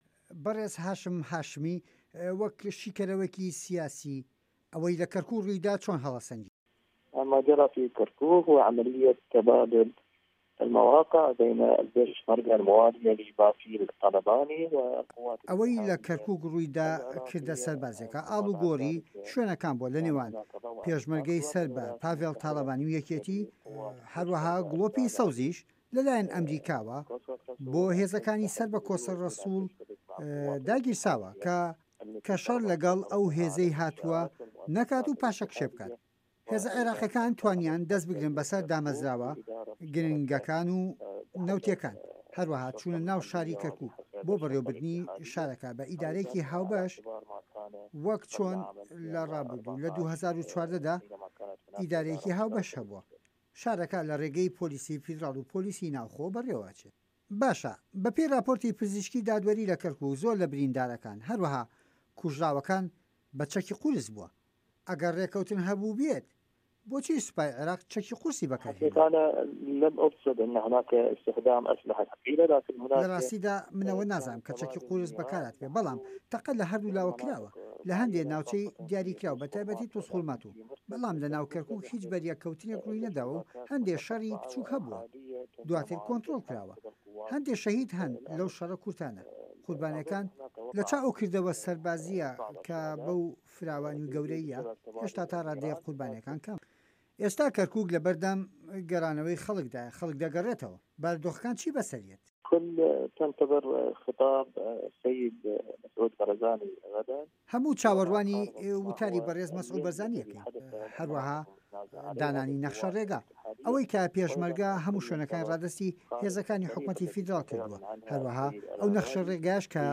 هه‌رێمه‌ کوردیـیه‌کان - گفتوگۆکان